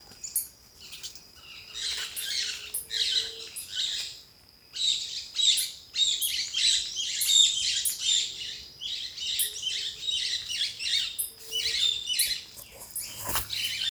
Pitanguá (Megarynchus pitangua)
Nombre en inglés: Boat-billed Flycatcher
Condición: Silvestre
Certeza: Fotografiada, Vocalización Grabada